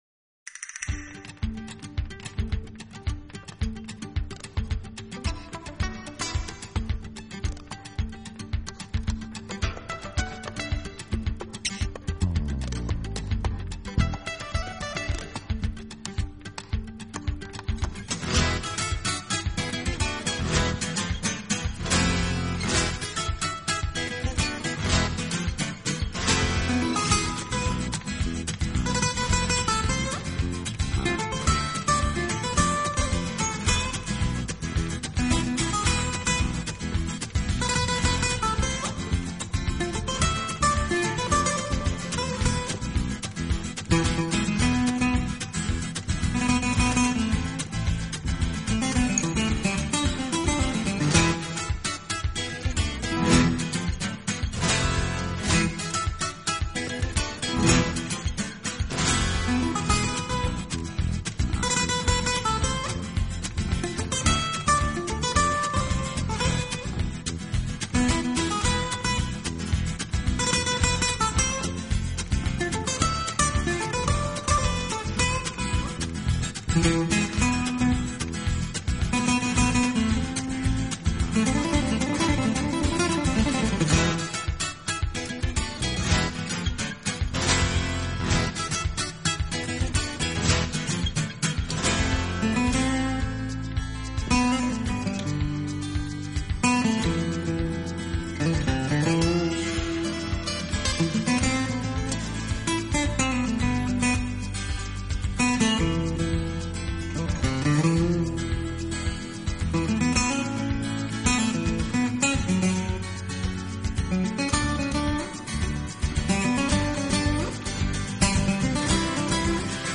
到的演奏风格-–他个人只简单称为“手指技法(finger style)”–-相当于是钢琴家在弹钢琴